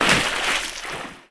Added water step/land sounds.